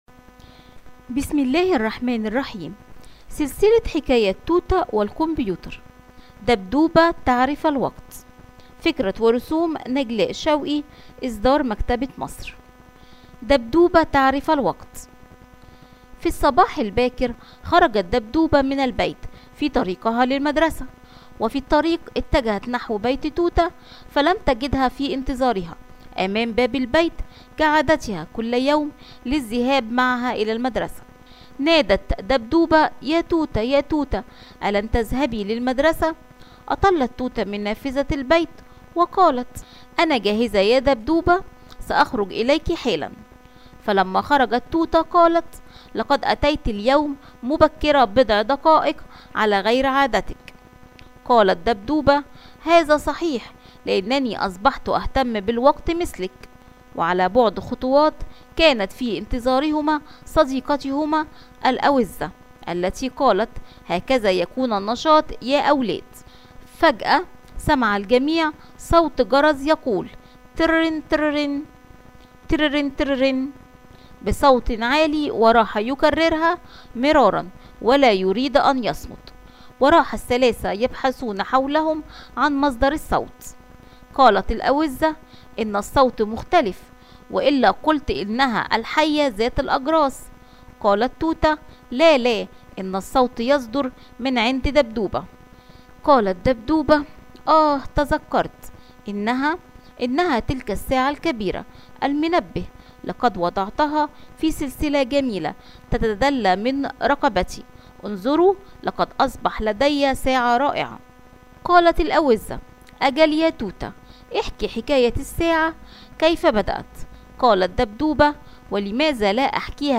Debdub Zamanı Biliyor – Arapça Sesli Hikayeler
Debdub-zamani-biliyor-arapca-sesli-hikayeler.mp3